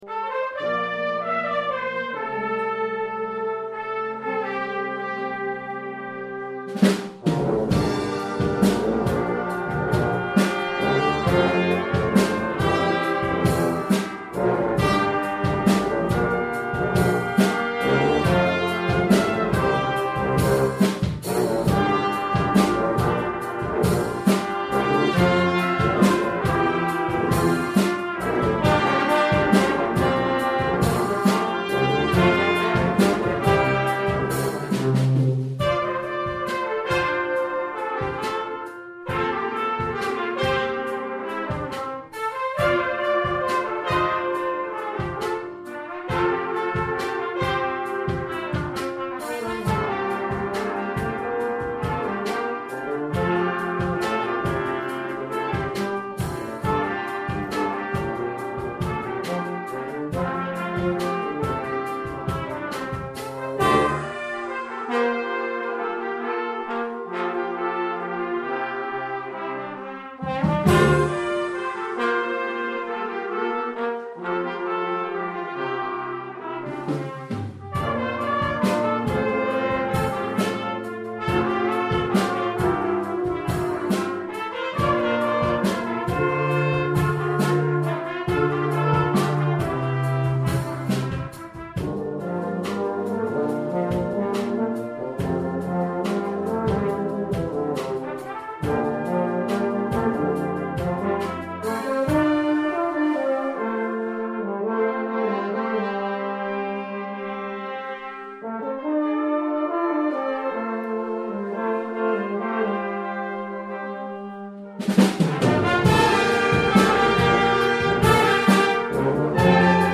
Brass Ensemble - Paradise
Musicians from Years 7 - 13.